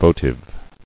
[vo·tive]